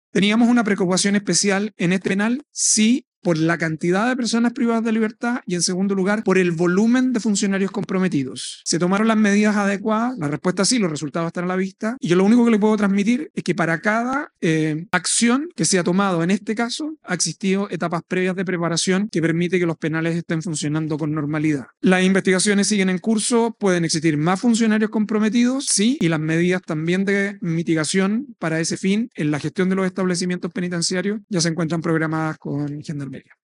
En conversación con 24 Horas, el secretario de Estado sostuvo que los hechos revelan casos graves de corrupción y recalcó que se requiere una depuración al interior de la institución.